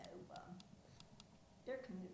Weston (W. Va.)